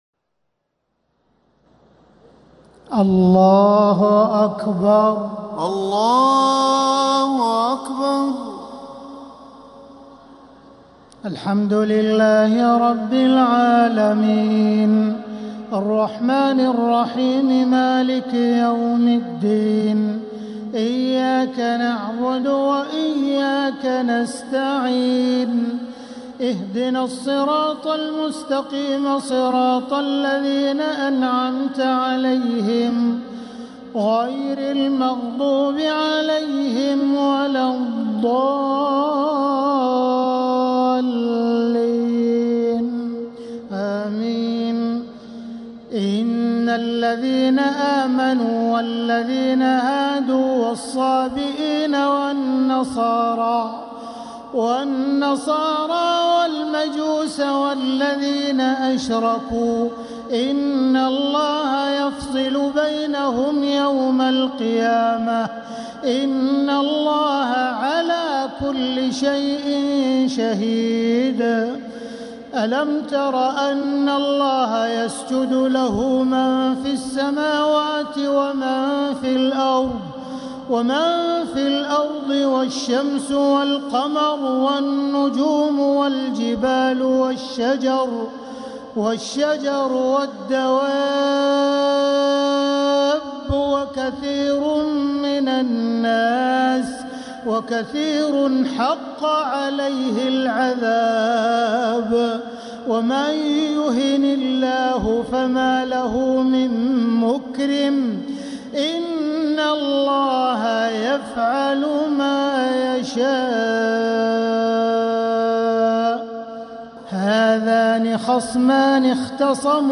تهجد ليلة 21 رمضان 1447هـ من سورة الحج (17-37) | Tahajjud 21st night Ramadan 1447H Surah Al-Hajj > تراويح الحرم المكي عام 1447 🕋 > التراويح - تلاوات الحرمين